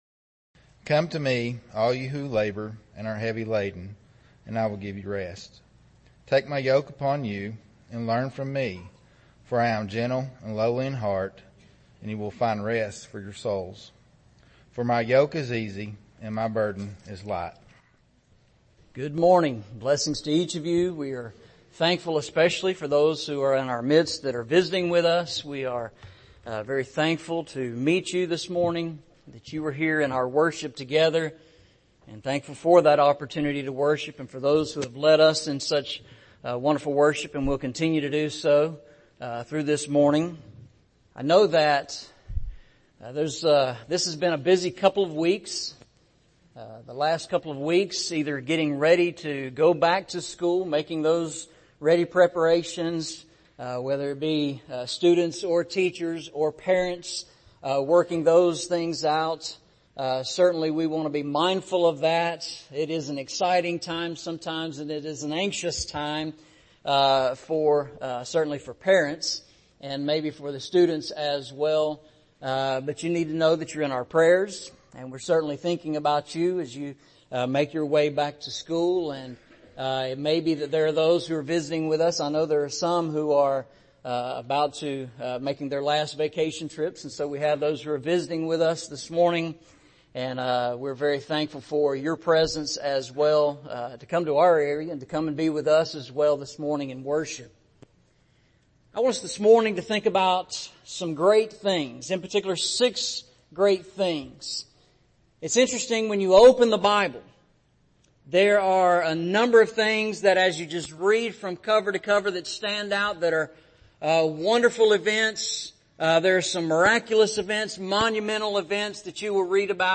Eastside Sermons